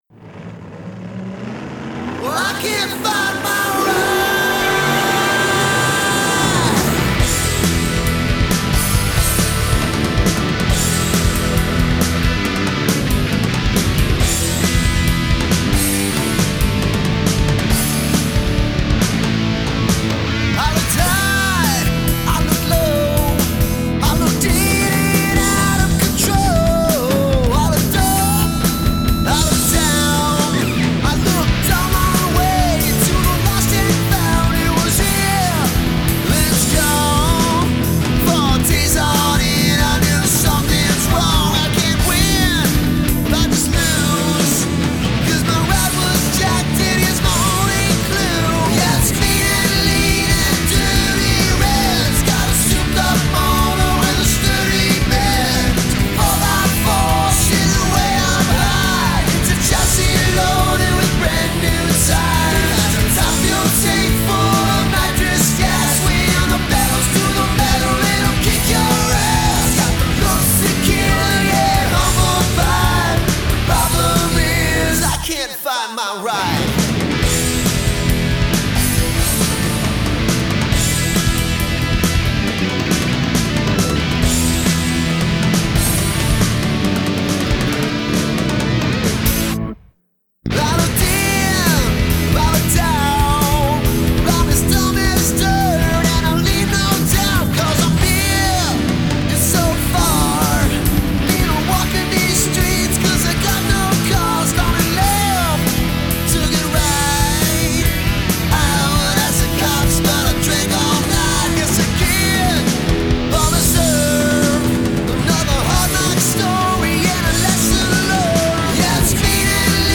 music_smorgasbord_icantfindmyride_male.mp3